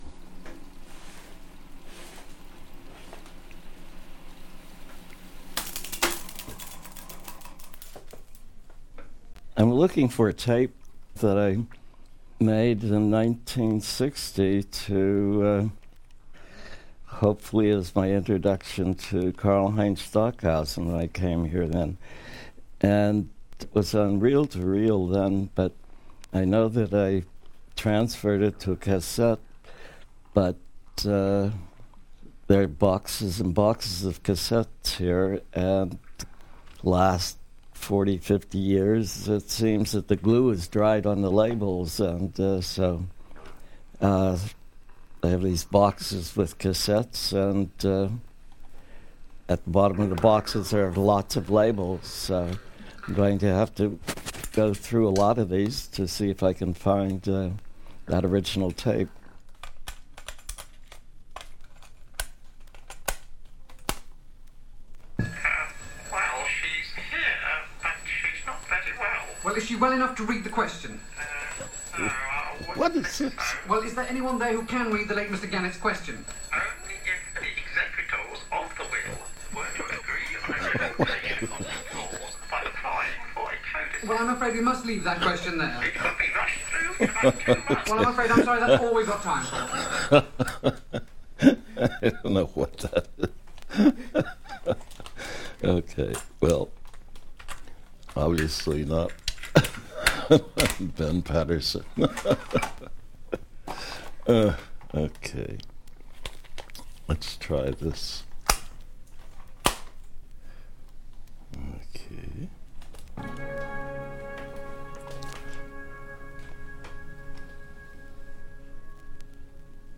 Ben Patterson fue un músico y fundador del movimiento Fluxus, hace algunos años se presentó en la grandiosa estación WFMU para presentar un trabajo que realizó sobre el compositor Karlheinz Stockhausen y que tal vez se encontraba en alguno de sus casetes de su colección particular.
En su búsqueda nunca encuentra el trabajo inédito, sin embargo creo que eso es su trabajo inédito, es decir, un performance radiofónico de una supuesta búsqueda de un trabajo inédito, no se esperaba menos de uno de los fundadores de Fluxus:
WFMU-Ben-Patterson.mp3